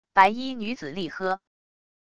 白衣女子厉喝wav音频